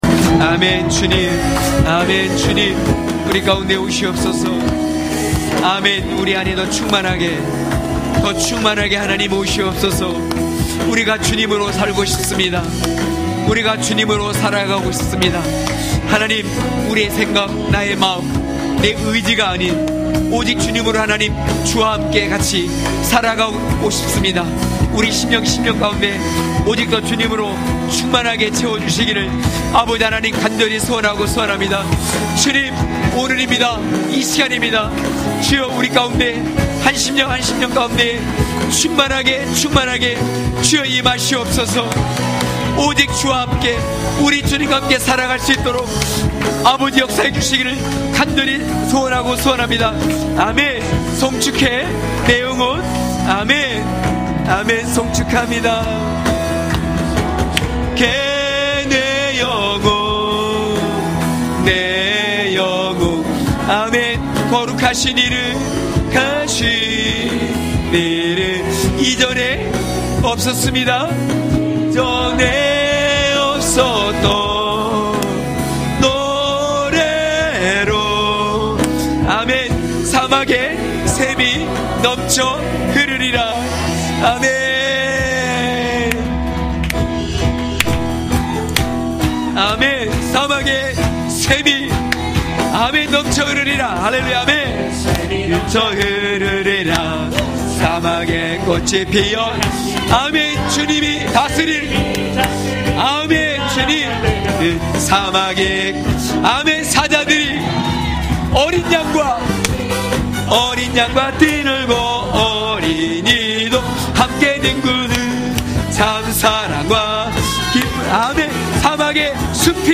강해설교 - 17.언약의 땅에서도 종이 되다!!(느9장30~38절).mp3